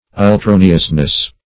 -- Ul*tro"ne*ous*ness , n. [Obs.]
ultroneousness.mp3